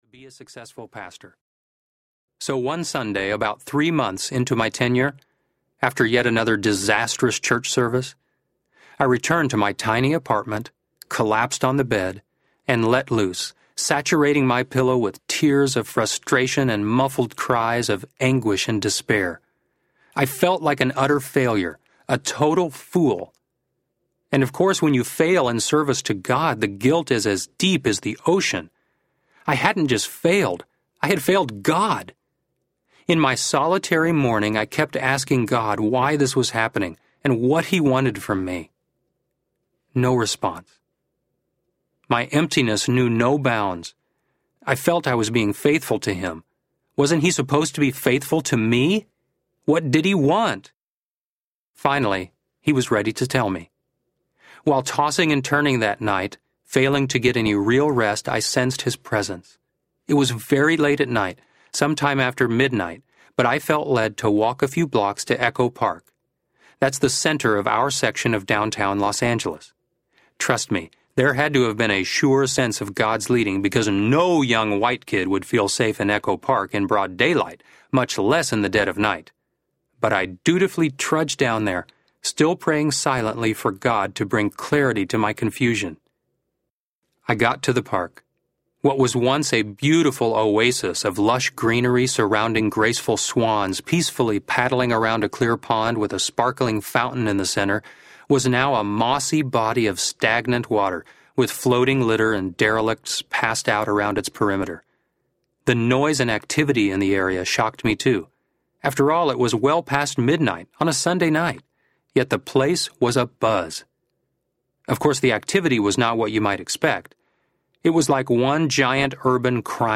The Cause Within You Audiobook
Narrator
6.25 Hrs. – Unabridged